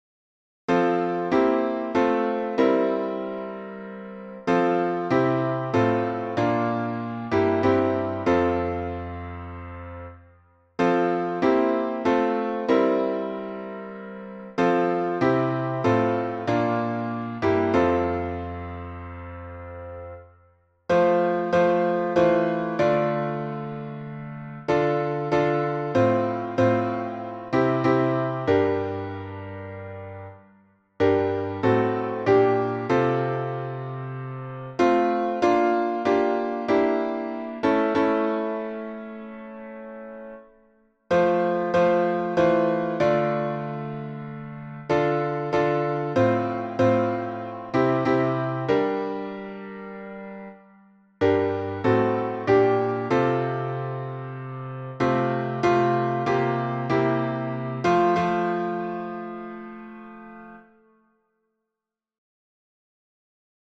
We Rest on Thee — alternate timing.
Key signature: F major (1 flat) Time signature: 4/4